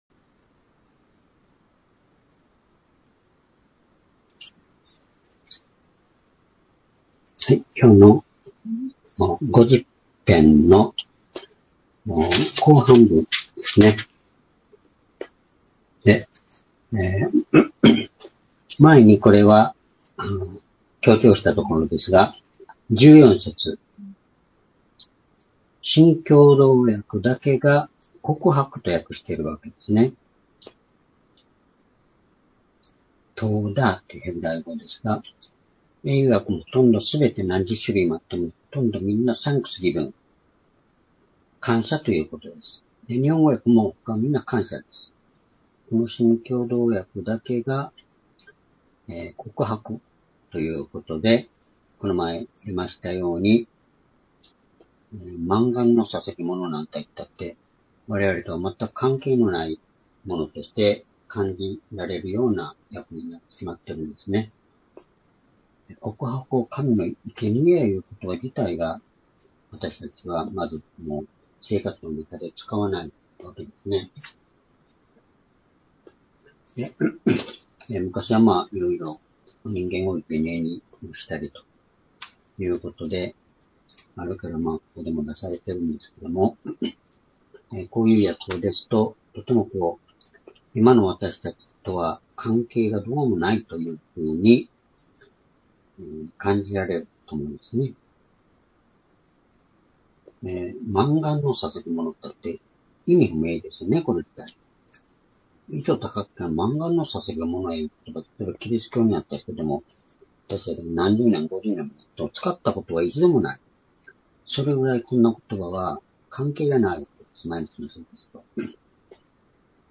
（主日・夕拝）礼拝日時 ２０２５年６月１７日（夕拝） 聖書講話箇所 「感謝と救い」 詩編50編14節～23節 ※視聴できない場合は をクリックしてください。